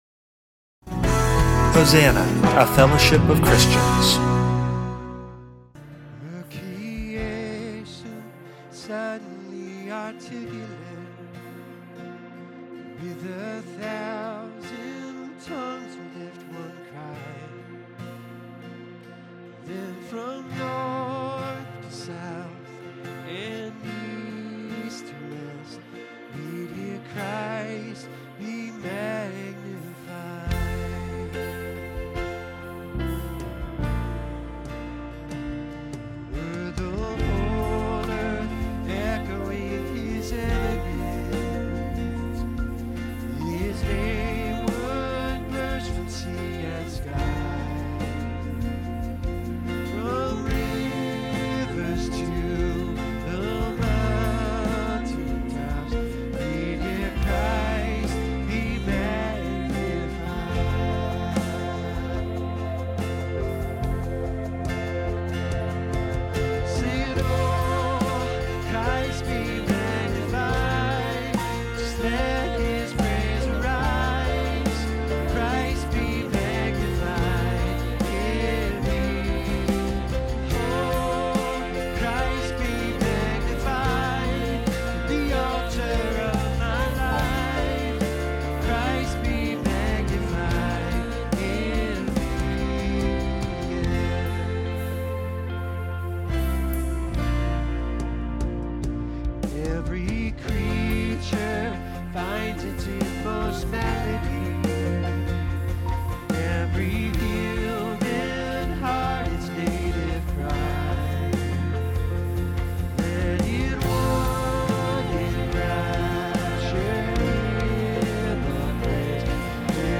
Genre: Spoken Word.